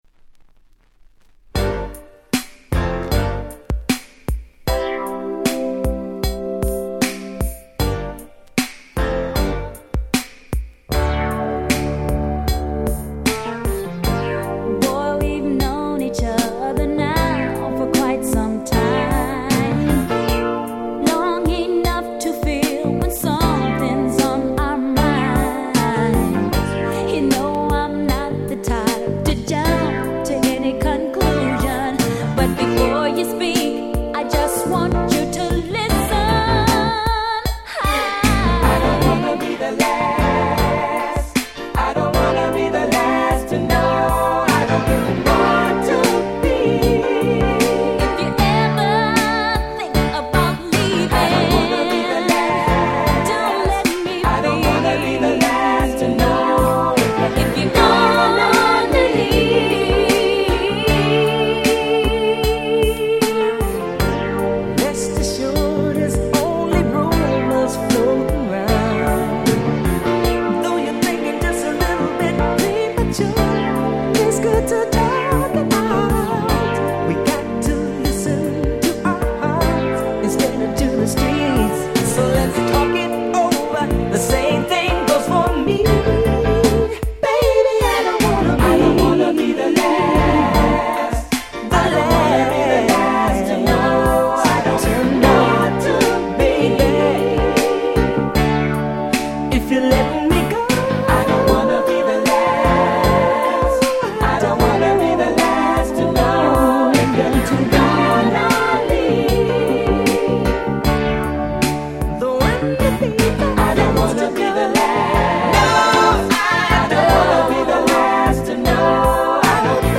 83' Great Slow/Mid♪